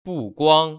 [bùguāng] 부꾸앙  ▶